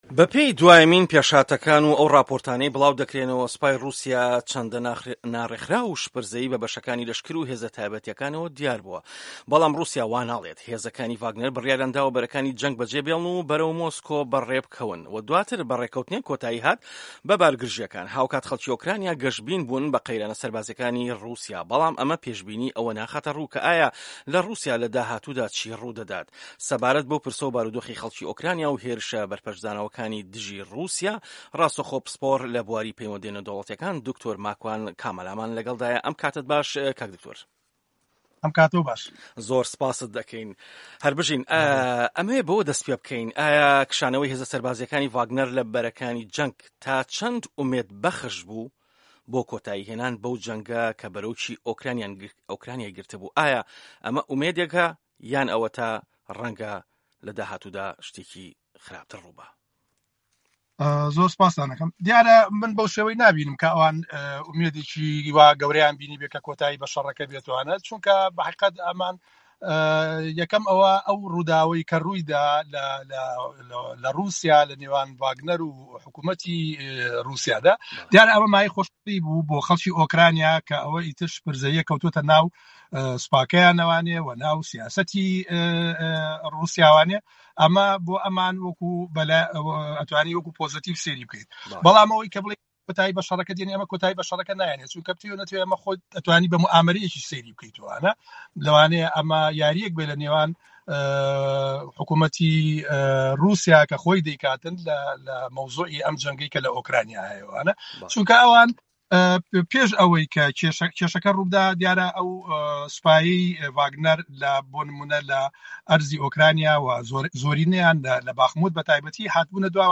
جیهان - گفتوگۆکان